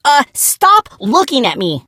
gus_lead_vo_04.ogg